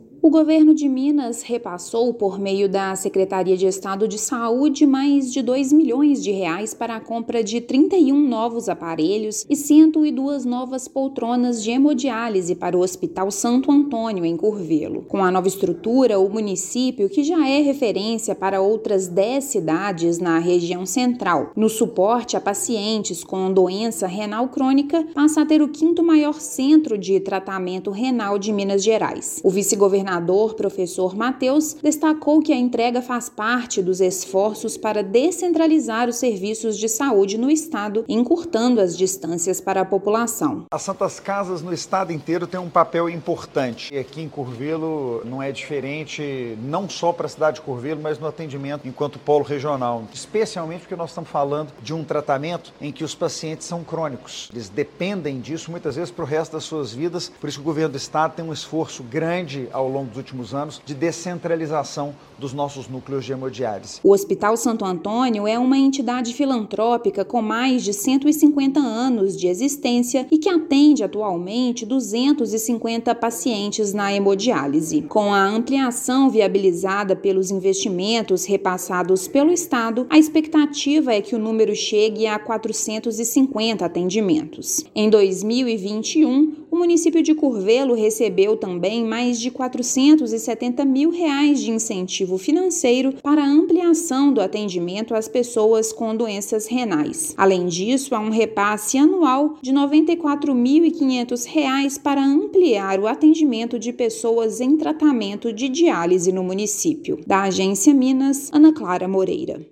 Nova estrutura coloca a cidade como o quinto maior centro de tratamento renal do estado. Política de ampliação já reduziu em 60% a distância percorrida por pacientes que precisam deste atendimento em Minas Gerais. Ouça matéria de rádio.